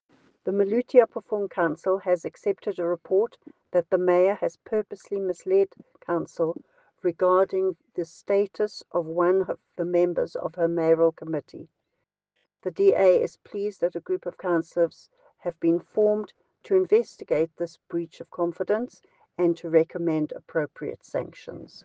English soundbite by Cllr Alison Oates,